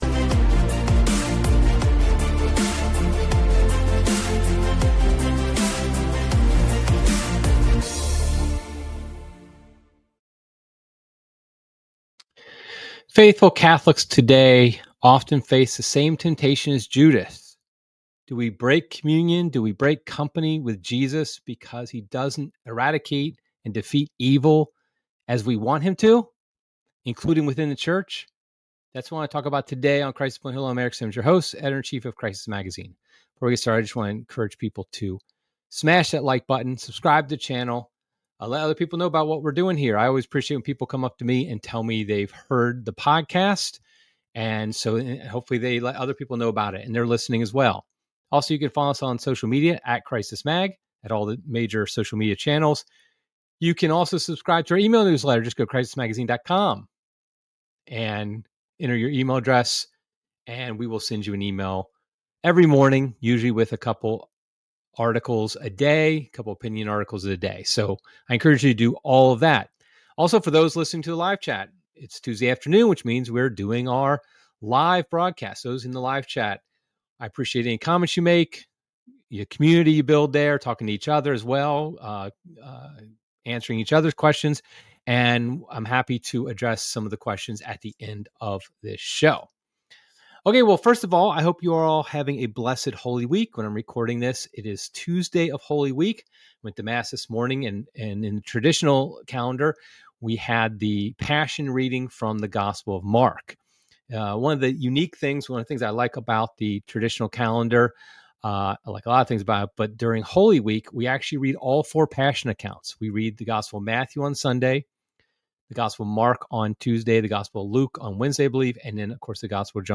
It's just me, no interview this time.